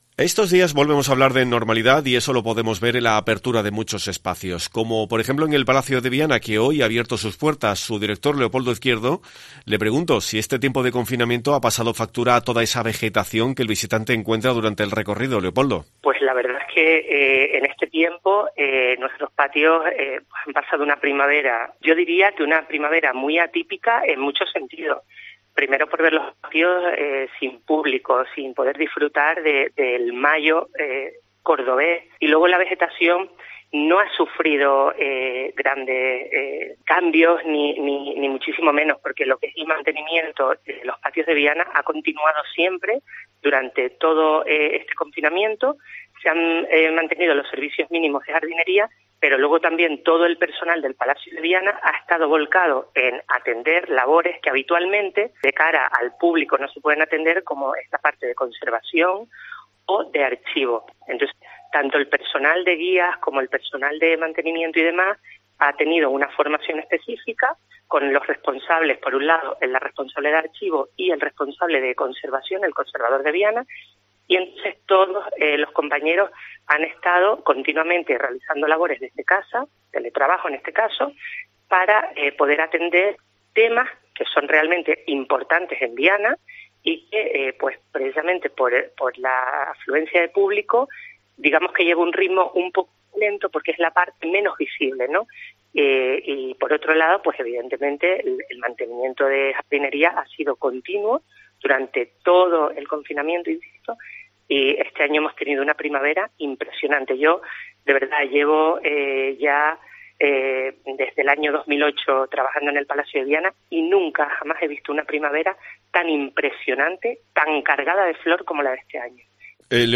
atiende la llamada